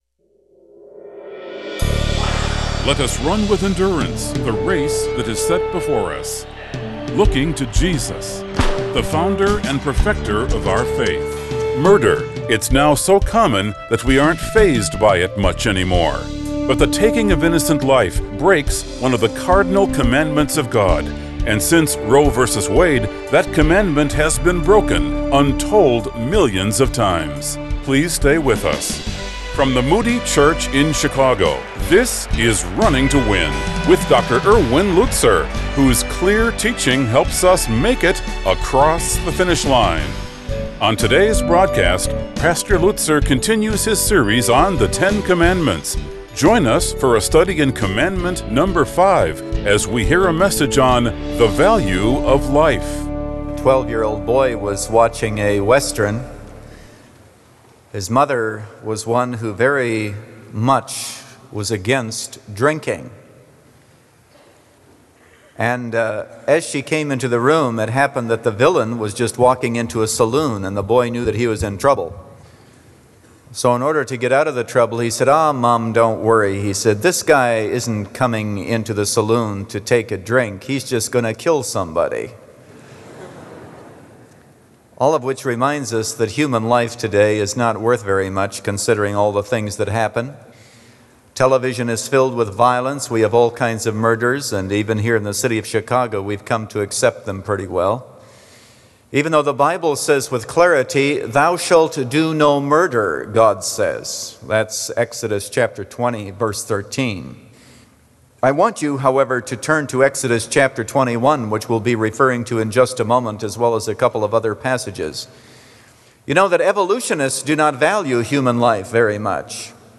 The Value Of Life – Part 1 of 3 | Radio Programs | Running to Win - 15 Minutes | Moody Church Media